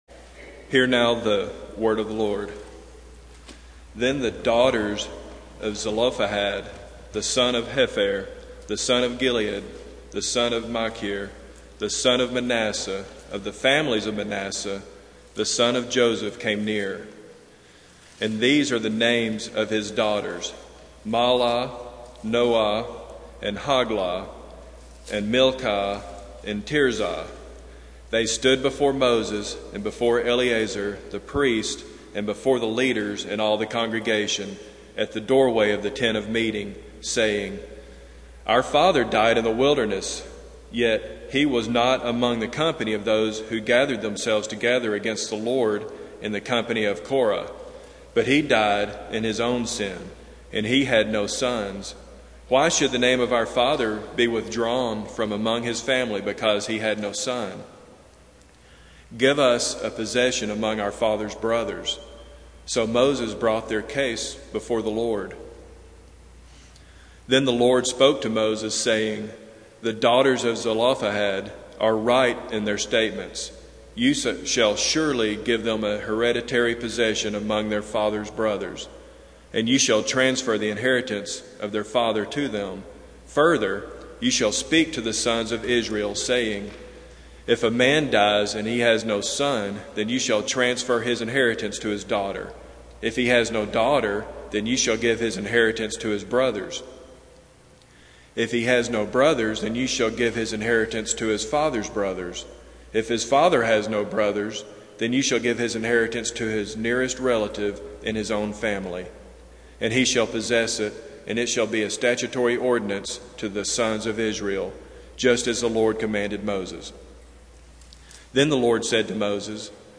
Passage: Numbers 27:1-12 Service Type: Sunday Morning